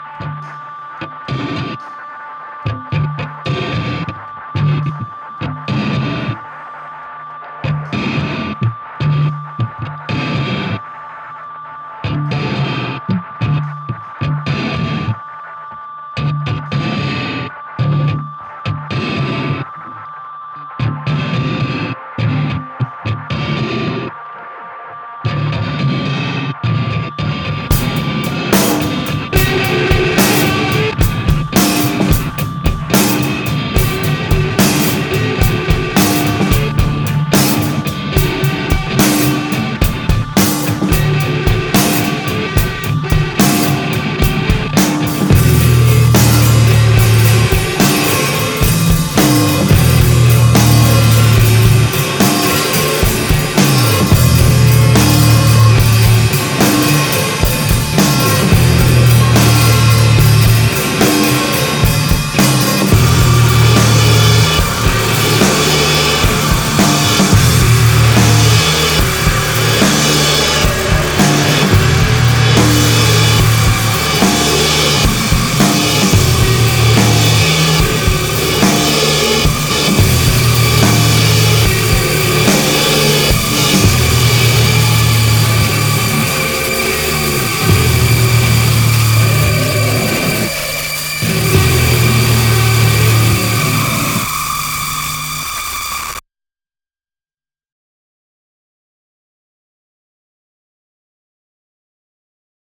Attached is an MP3 file that pops every time when played through my SB3.